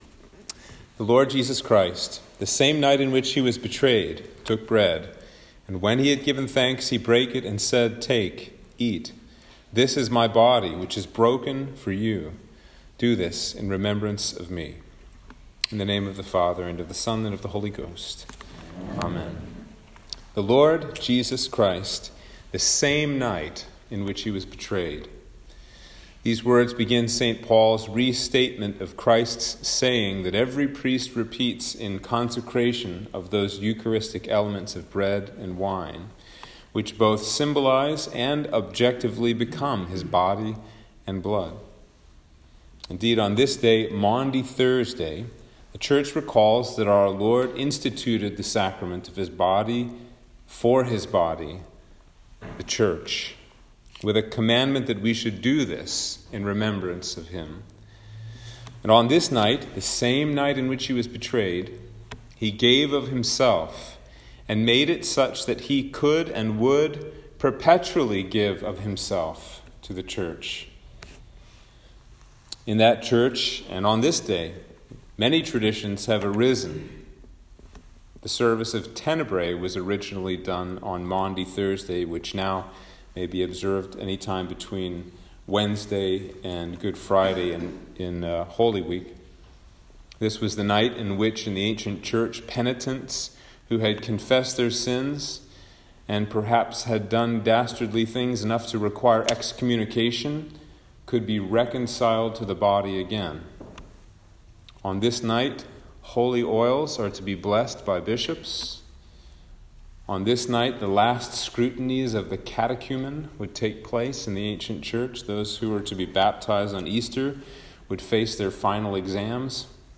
Sermon for Maundy Thursday
Sermon-for-Maundy-Thursday.m4a